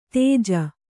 ♪ tēja